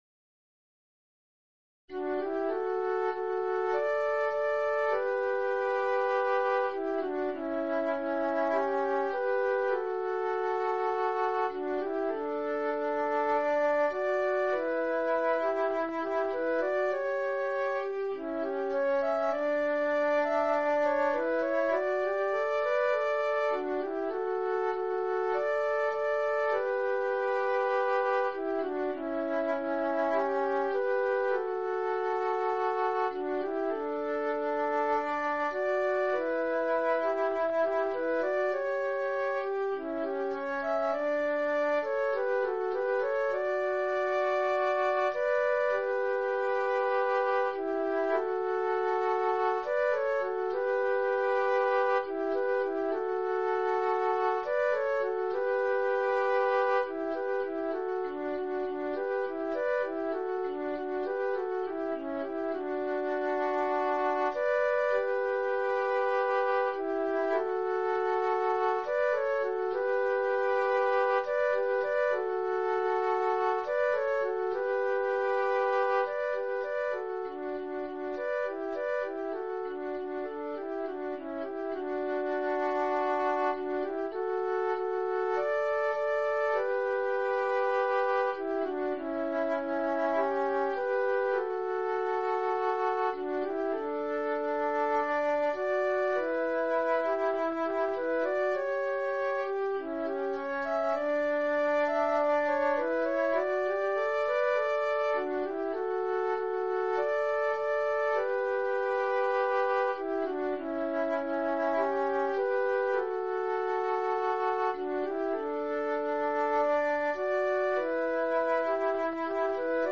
Marcha de Procesión – Pezas para Gaita Galega
Dúo
san-roques-dog-2-voces-d.mp3